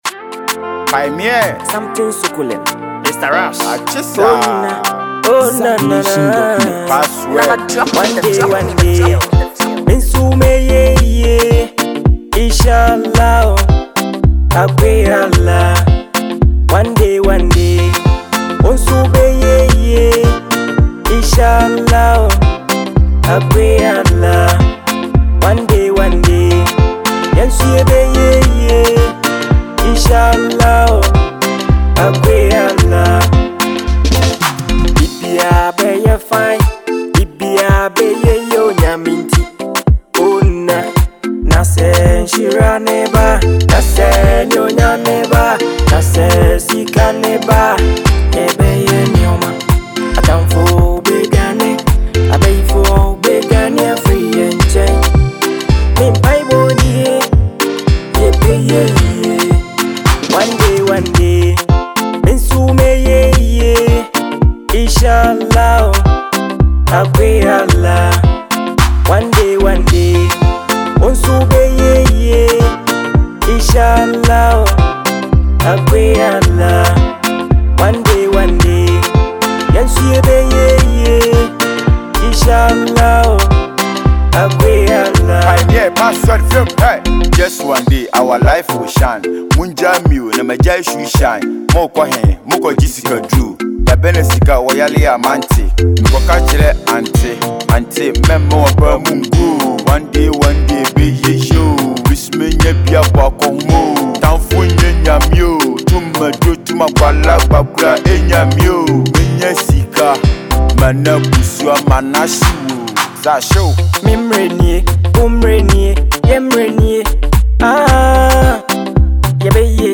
soulful voice
smooth flow